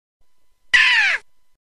Blockland Ouch Sound Buttons
Blockland ouch
blockland-death-sound-192-kbps.mp3